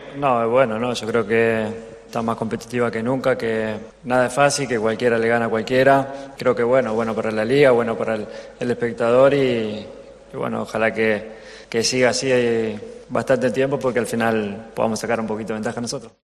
AUDIO: El argentino valoró lo que llevamos de temporada una vez recibido el Premio Pichichi por parte del Diario MARCA.